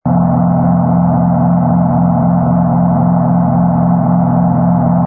engine_ku_freighter_loop.wav